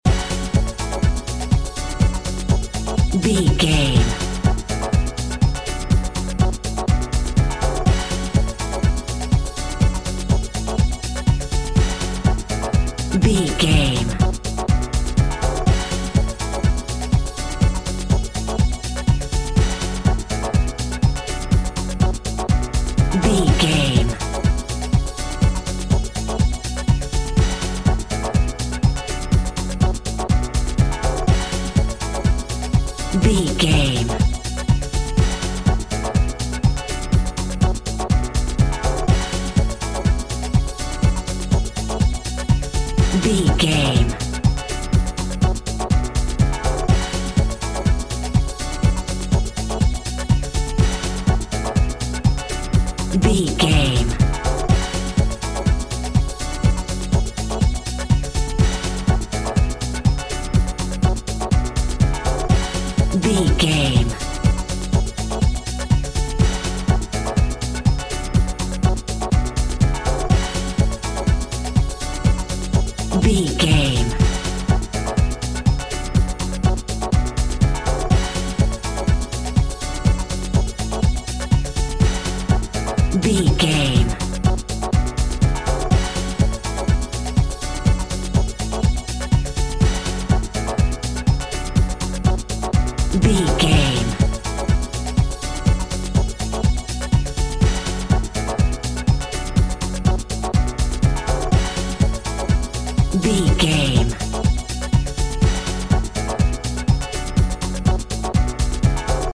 Aeolian/Minor
F#
synth drums
synth leads
synth bass